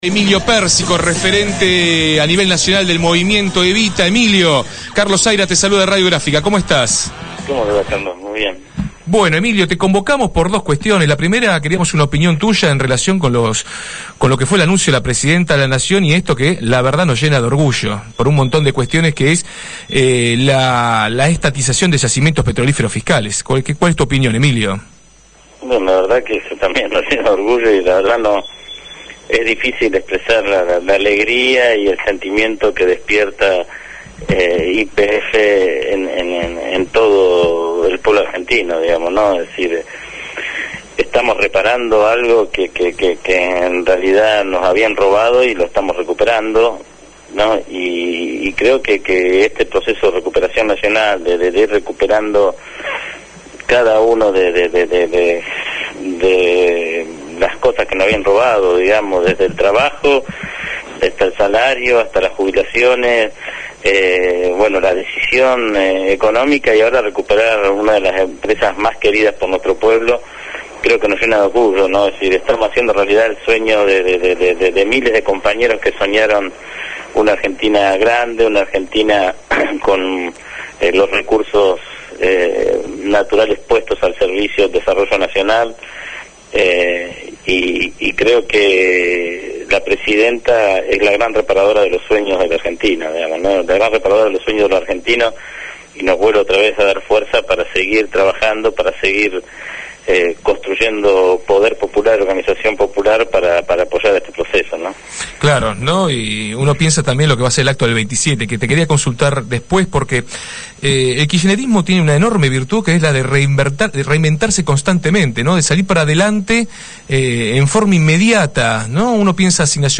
Emilio Pérsico, referente a nivel nacional del Movimiento Evita, habló en Desde el Barrio.